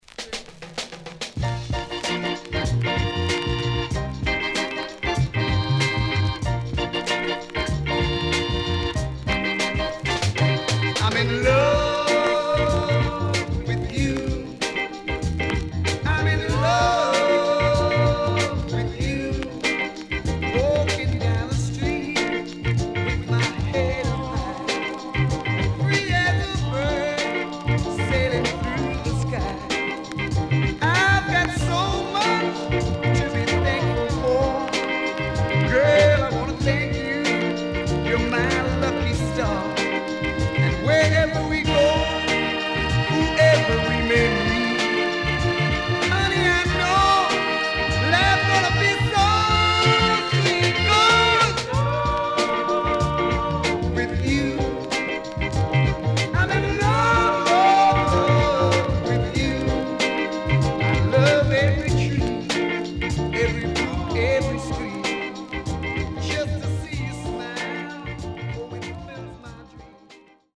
SIDE A:全体的にチリノイズがあり、少しプチノイズ入ります。